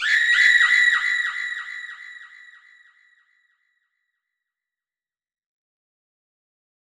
SouthSide Chant (27).wav